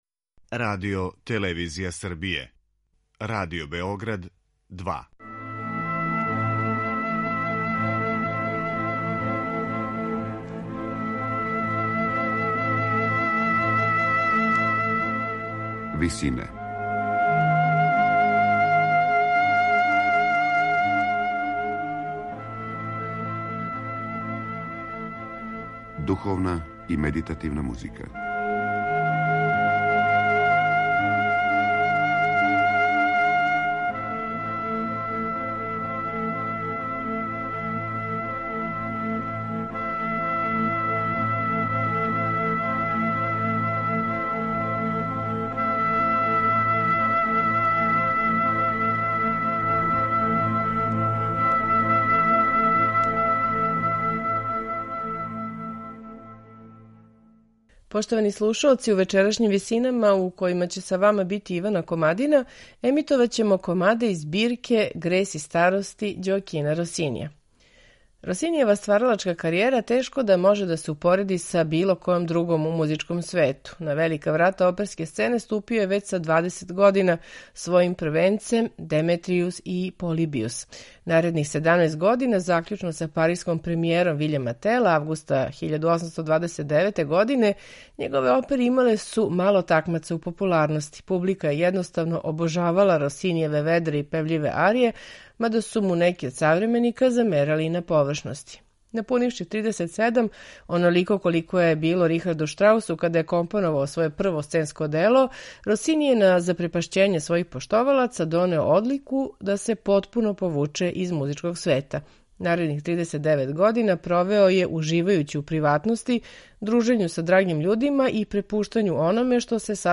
Из ових збирки издвојили смо меланхоличне комаде, који откривају једну мање познату страну Росинијеве личности. Лирске клавирске композиције